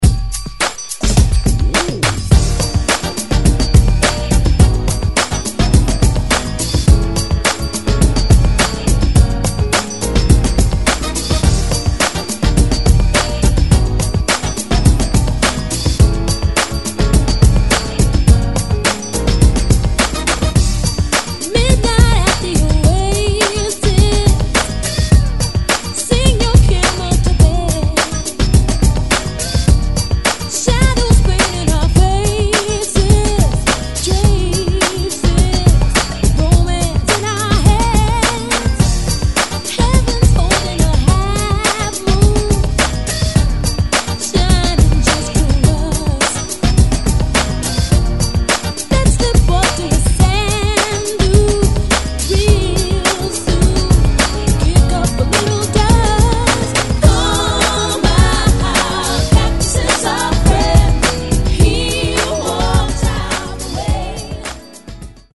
88 Bpm Genre: 70's Version: Clean BPM: 88 Time